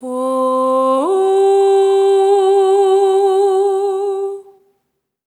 SOP5TH C4 -L.wav